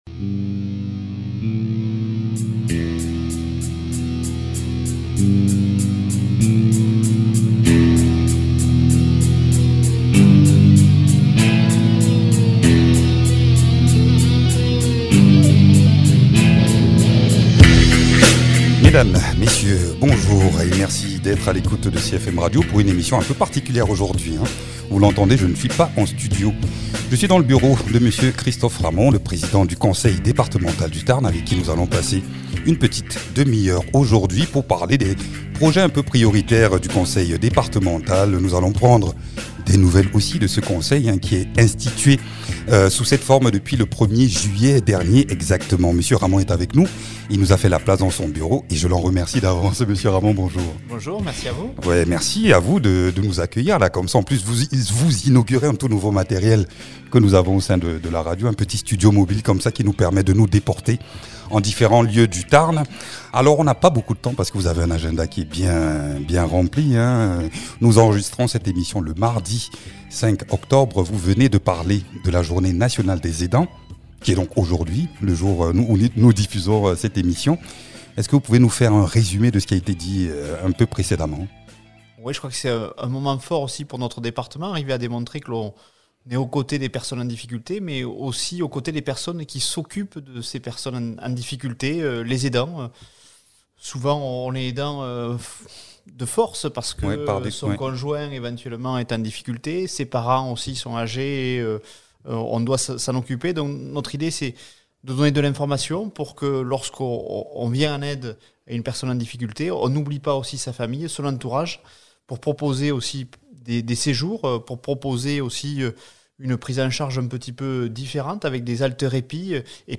Invité(s) : Christophe Ramond, président du conseil départemental du Tarn.